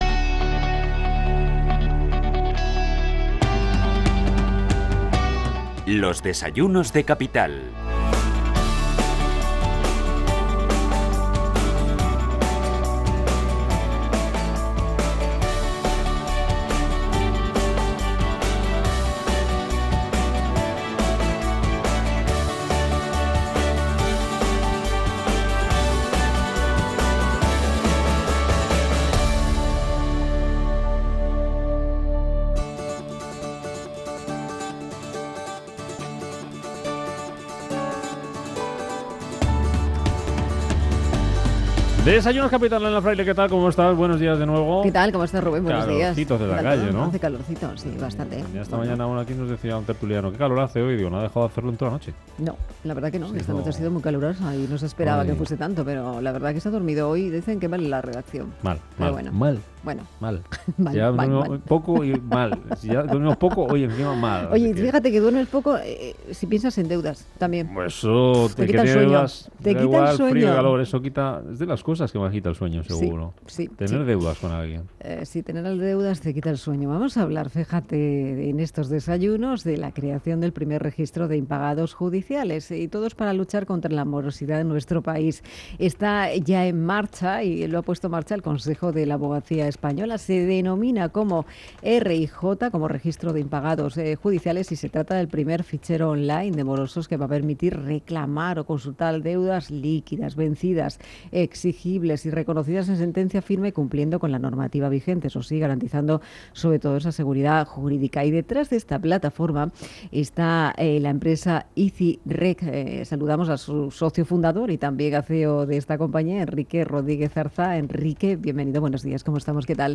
entrevistaintereconomia.mp3